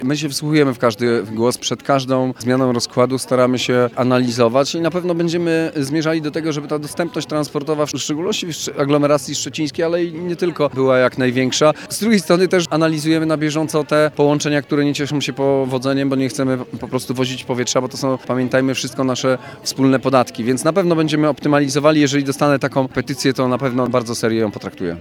Teraz głos w sprawie zabrał marszałek Olgierd Geblewicz.